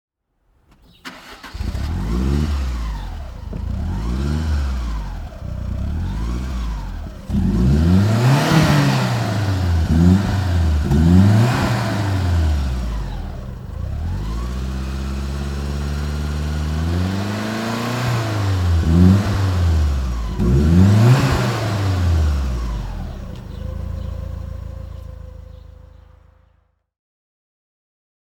Lancia Delta HF Integrale 8V (1991) - Starten und Leerlauf
Lancia_Delta_Integrale_8V.mp3